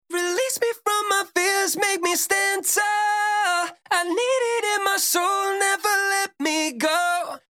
男声ボーカルを女性ボーカルに変えて仮歌を作成
▼元の男性ボーカル